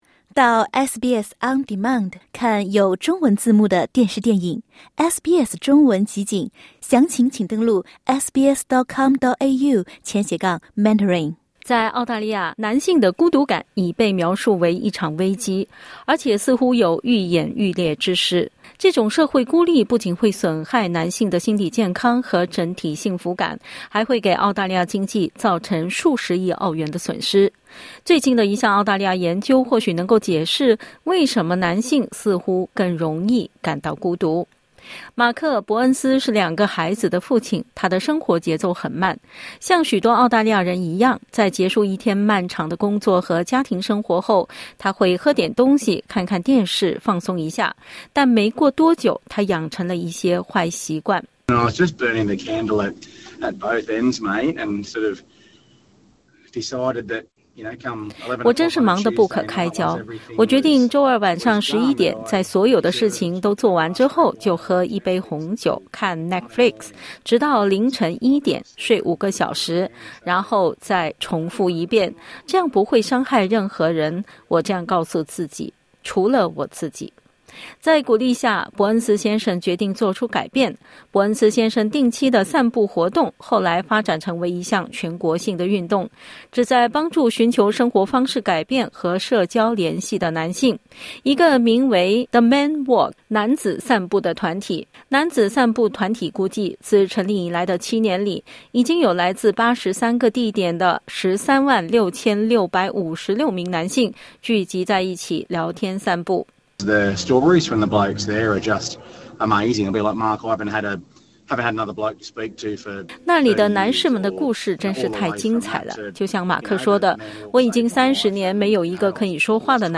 在澳大利亚，男性的孤独感已被描述为一场“危机”。 点击音频收听详细报道